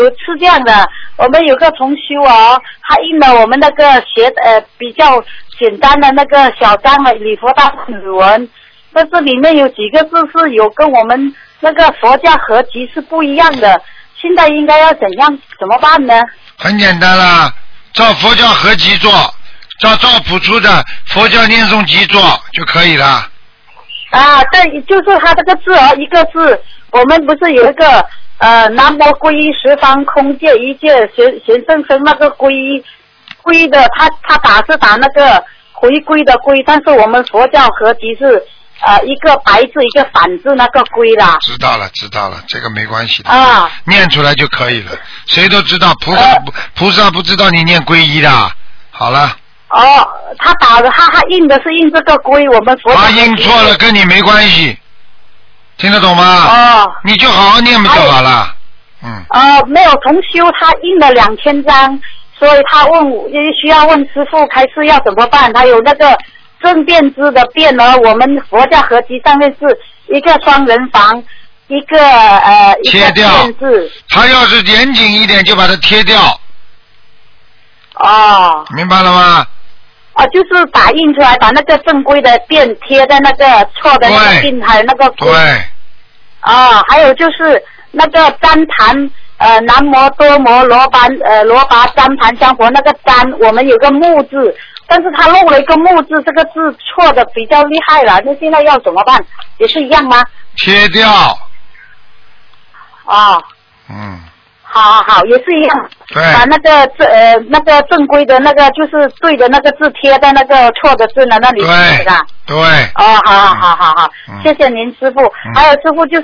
▶ 语 音 朗 读